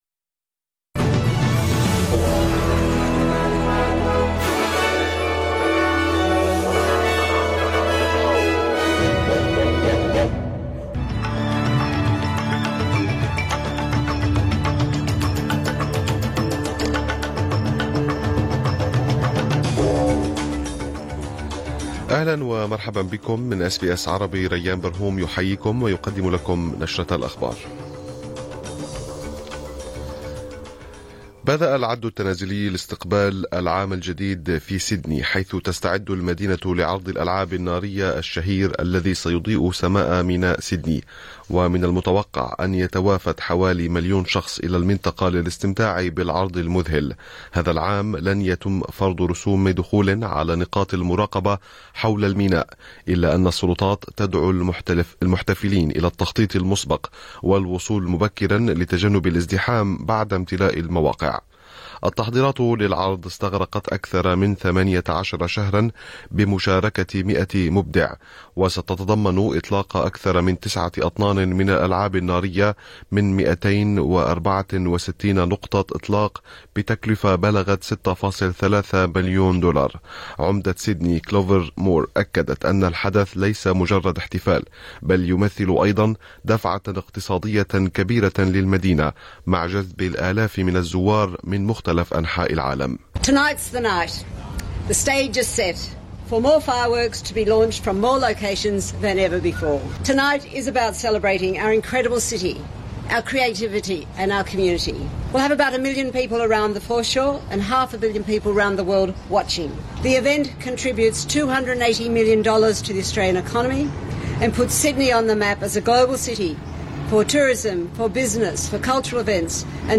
نشرة أخبار الظهيرة 31/12/2024